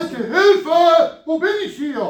wo bin ich hier Meme Sound Effect
This sound is perfect for adding humor, surprise, or dramatic timing to your content.